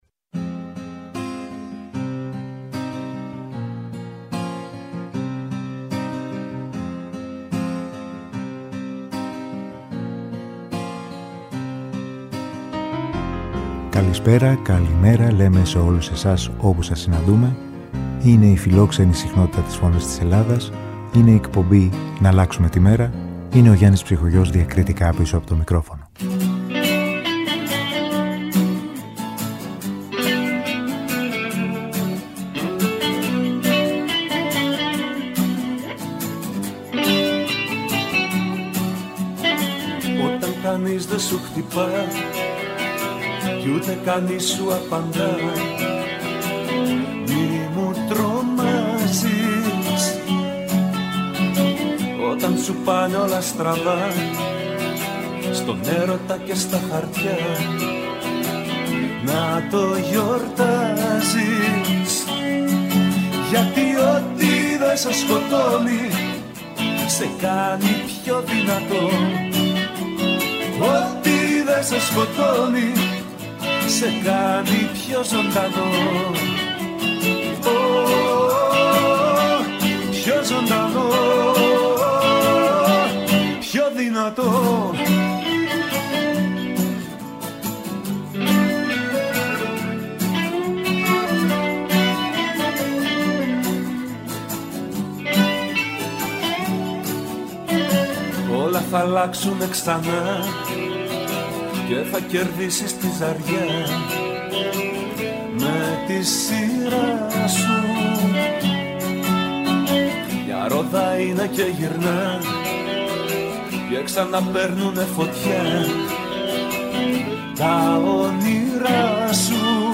Στην αρχή μιας νέας μέρας με μουσικές
Μουσική